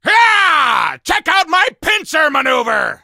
hank_ulti_vo_01.ogg